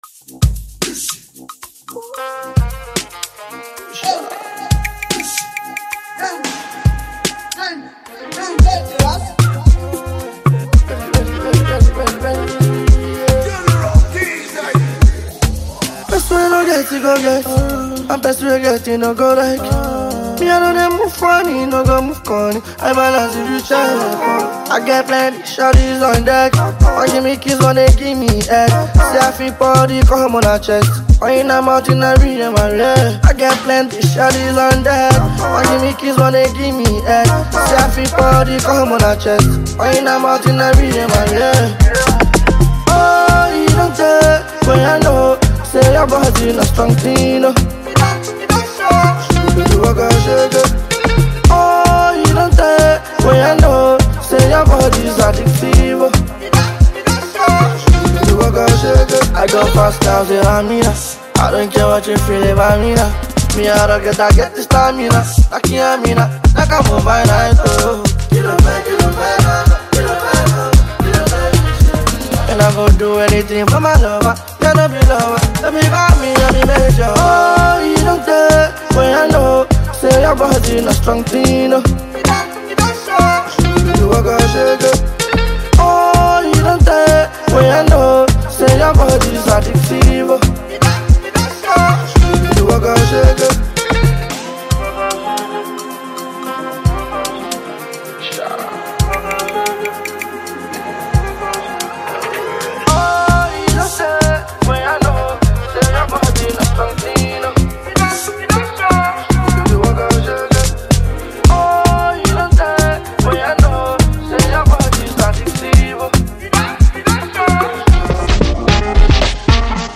and exceptional vocal delivery for devoted listeners.